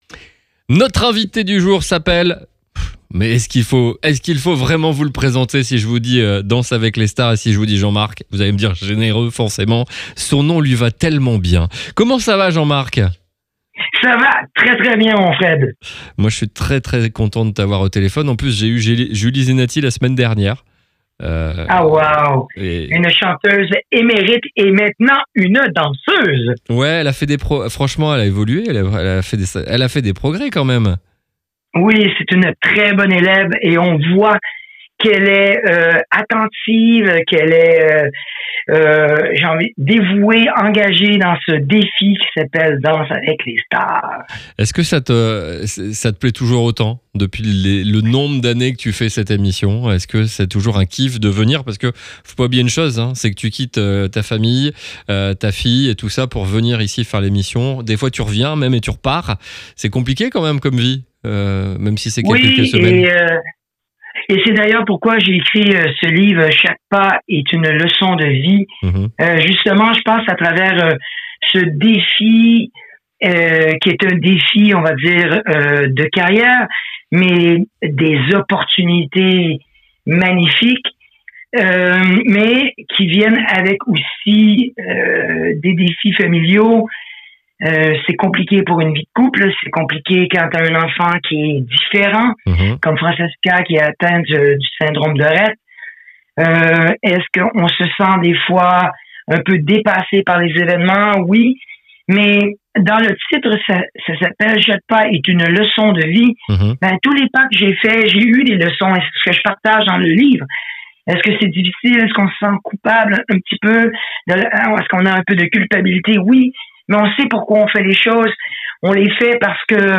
3. Les interviews exclusifs de RCB Radio